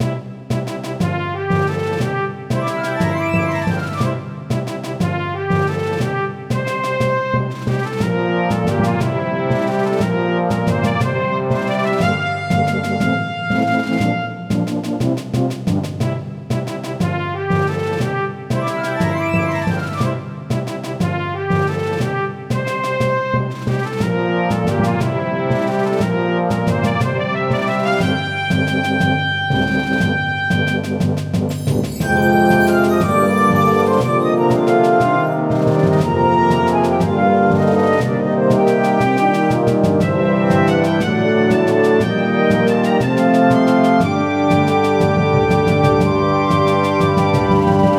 【イメージ】冒険のはじまり、船 など